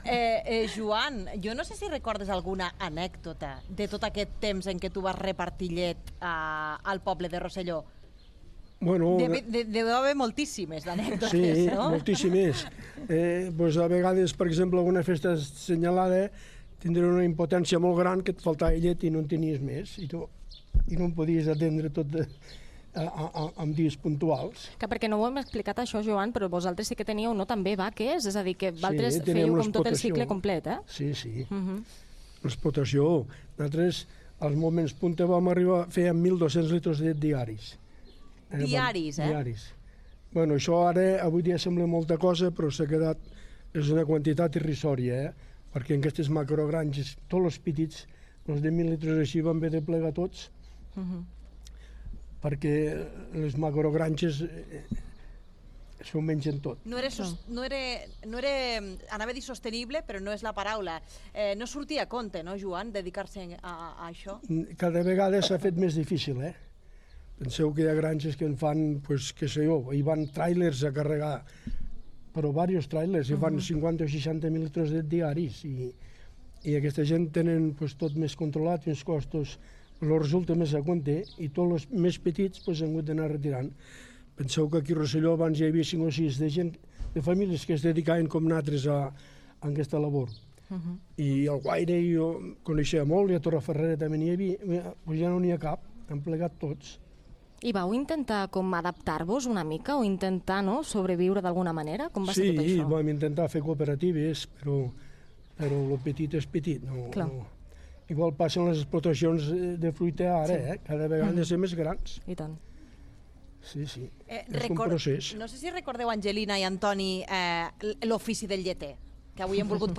Divulgació
FM